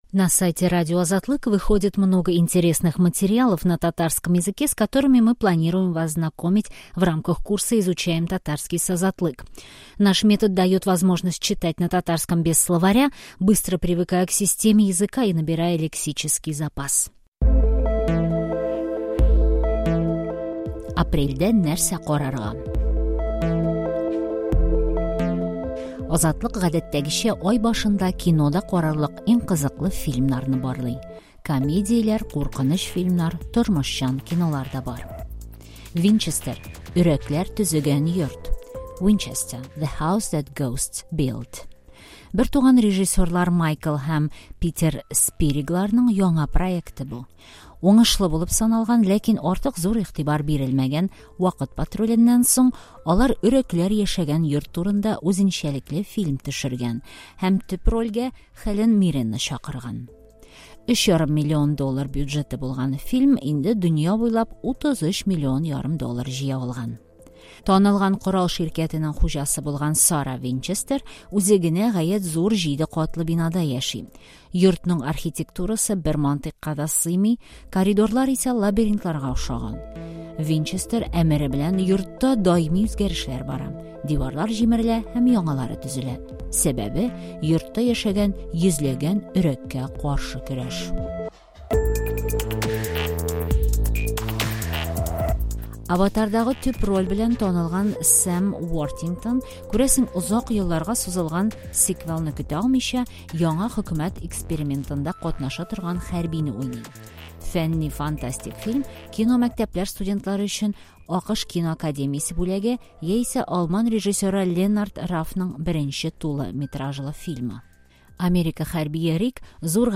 Также, обратите внимание, что специально для нашего проекта мы озвучили этот текст.